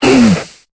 Cri de Chartor dans Pokémon Épée et Bouclier.